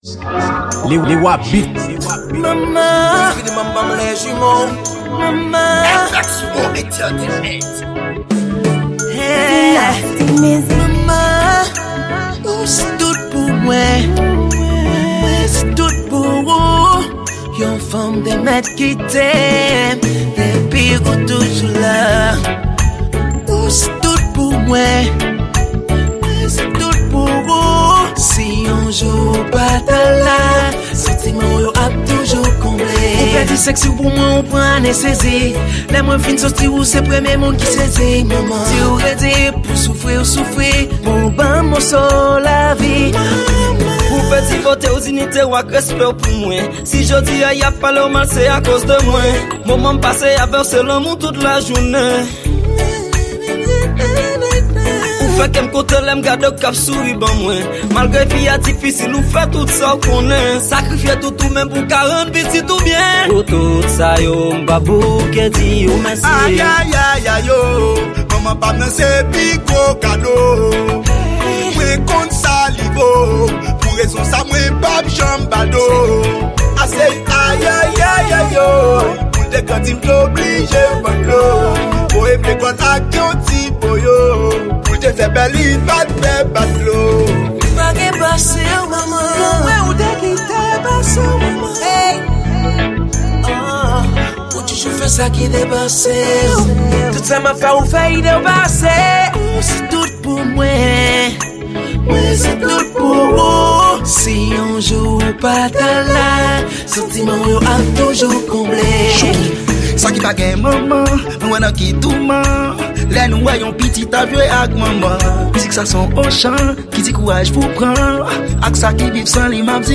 Genre:Varietes.